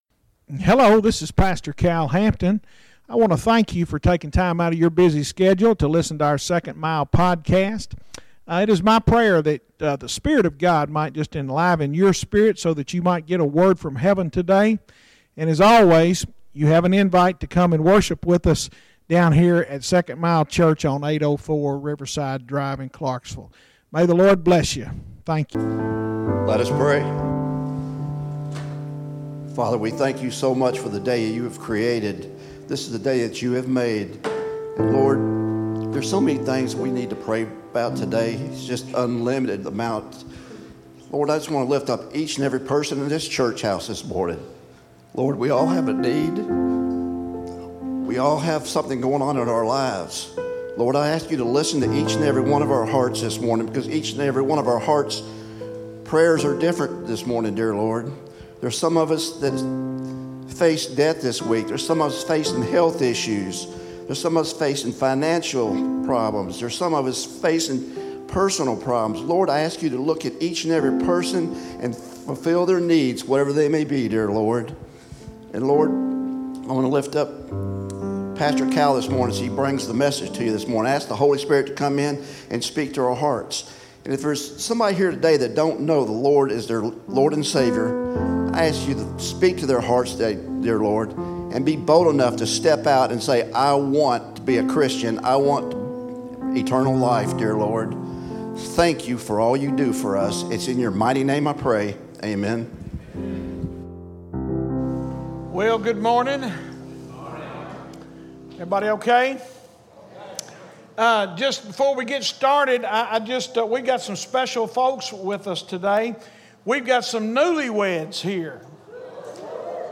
Sermons Archive - 2nd Mile Church